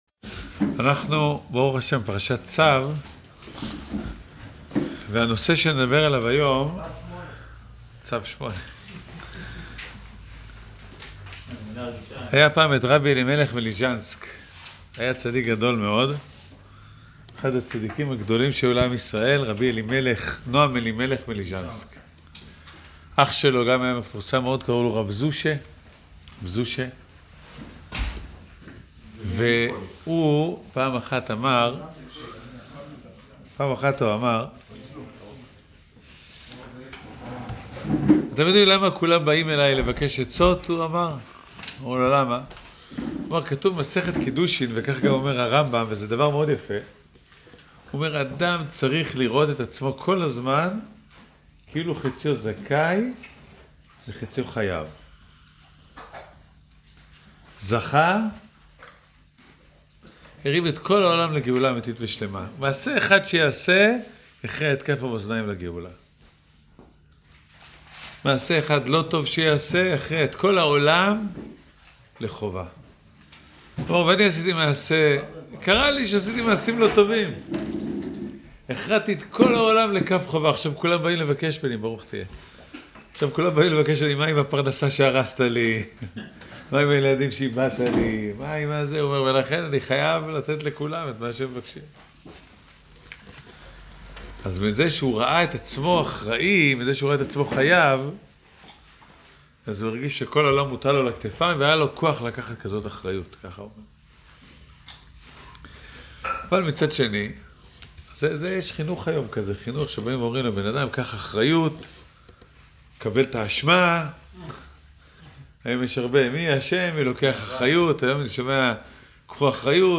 שיעור-קהילתי-תרומת-הדשן.-לא-לעצבות.mp3